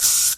Звуки аэрозоля
Звук лак для волос брызнули